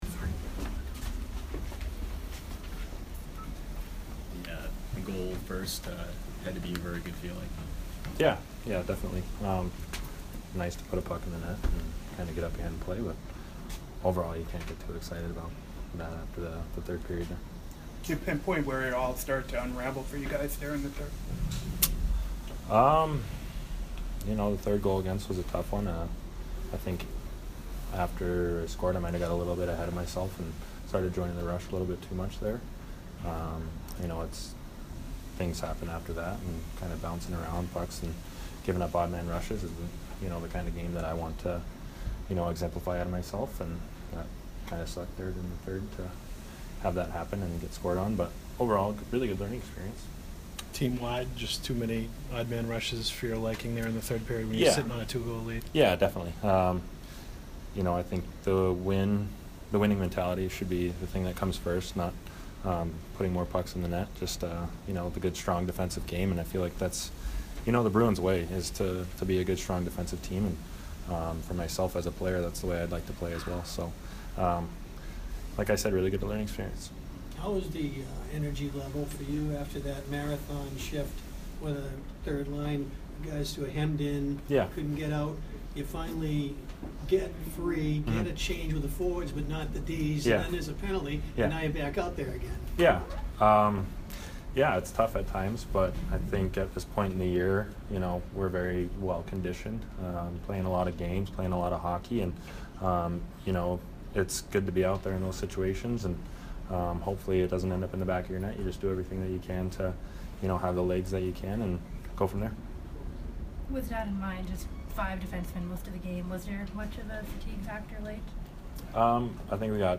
Brandon Carlo post-game 3/25